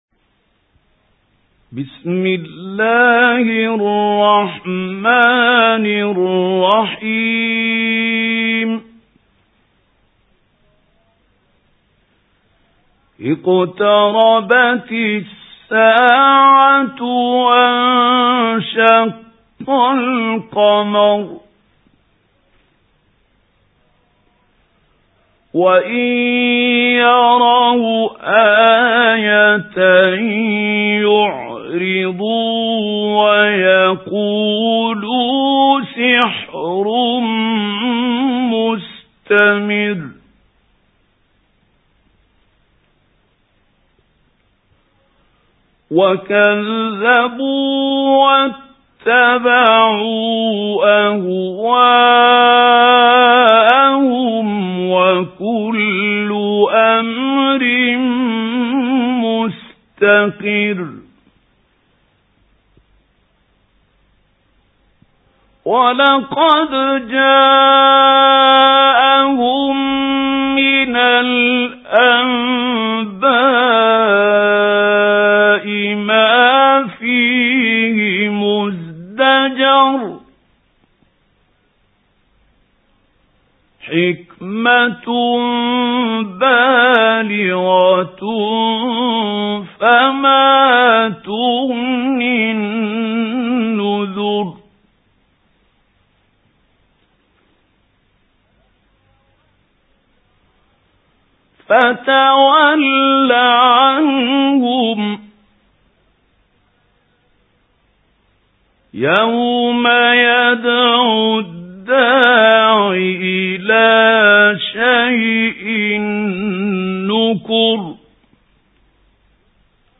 سُورَةُ القَمَرِ بصوت الشيخ محمود خليل الحصري